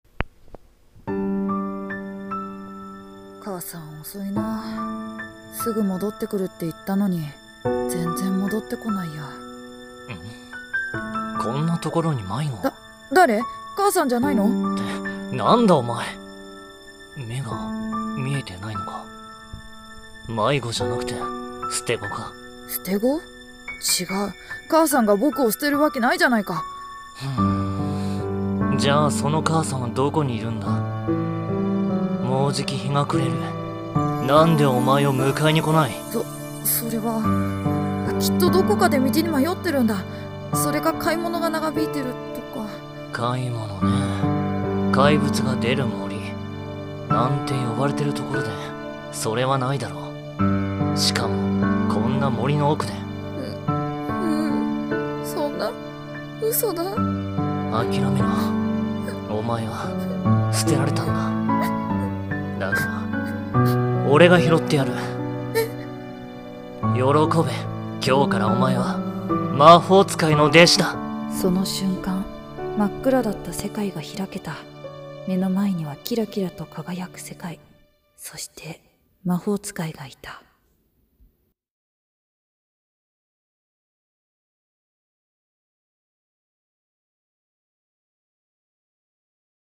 【２人声劇】 捨て子と魔法使い（捨て子目線）